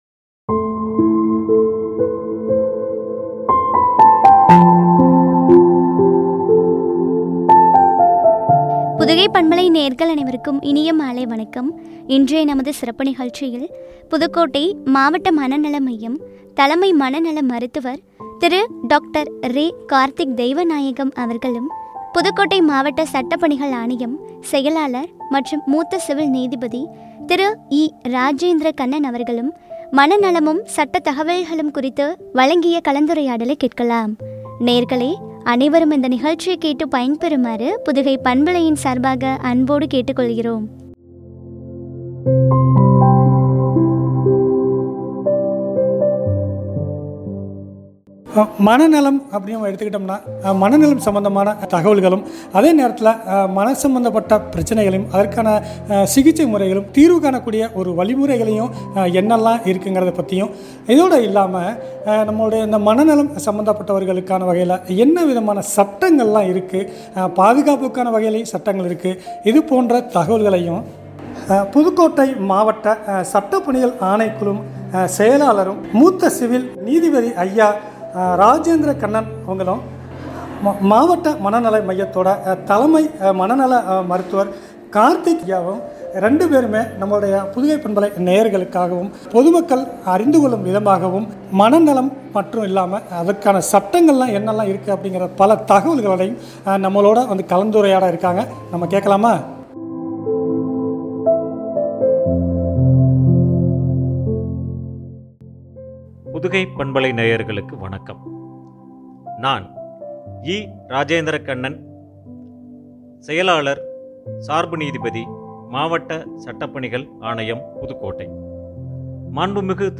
“மனநலமும் சட்ட தகவல்களும்” குறித்து வழங்கிய உரையாடல்.